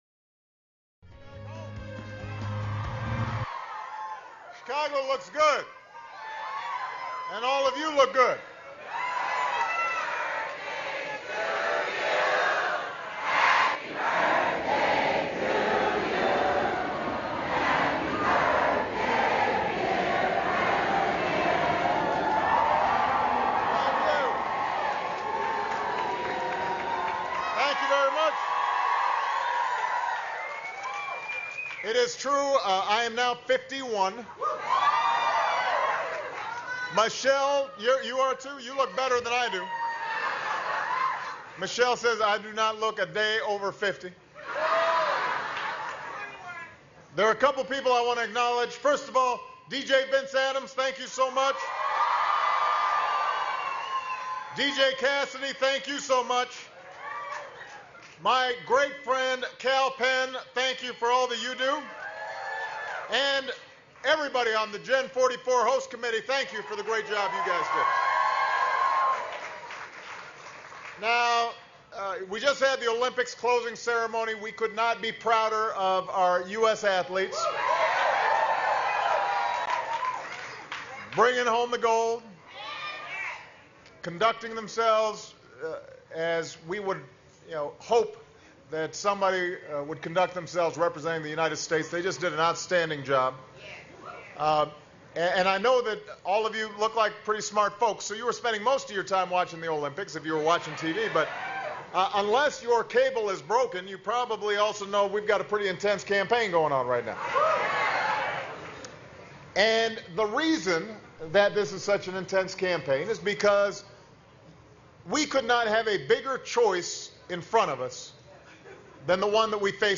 President Obama speaks to supporters at a campaign event held at the Bridgeport Art Center in Chicago